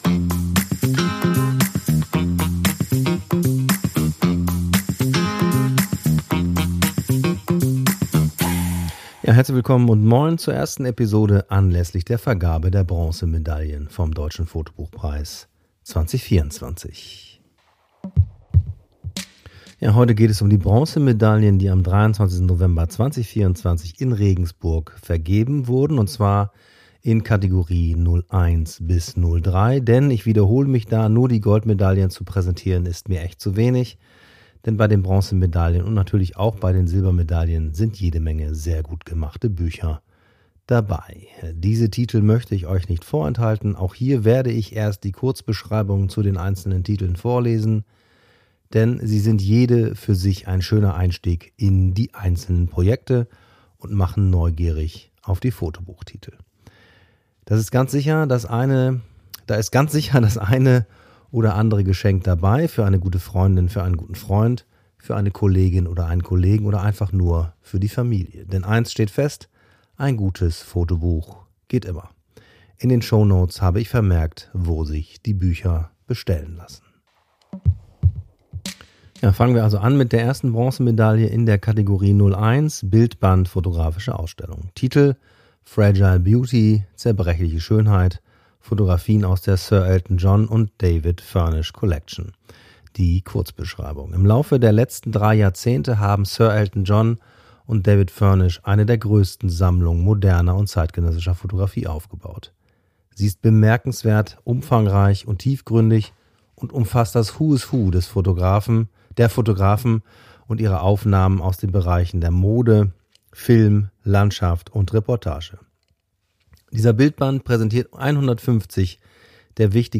Interviews mit Foto- und Kunstschaffenden
Gespräche mit Expertinnen und Experten aus der angewandten und künstlerischen Fotografie